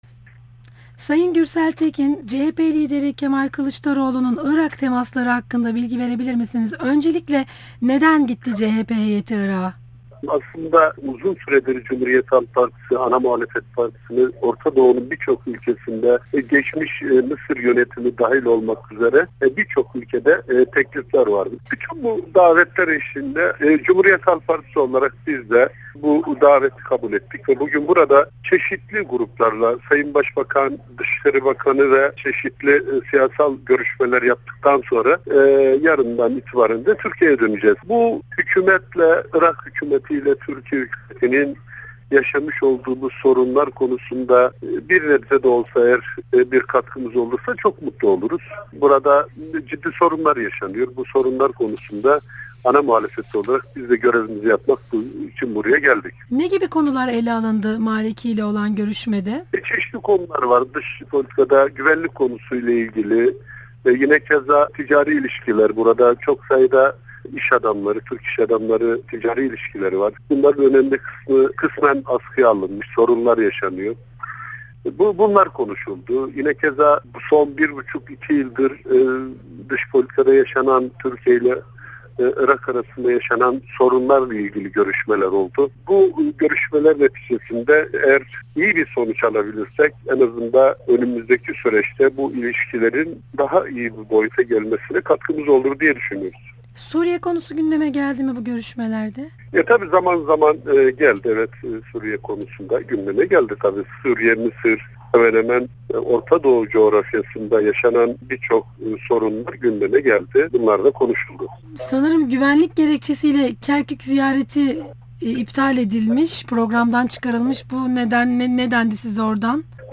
Gürsel Tekin ile Söyleşi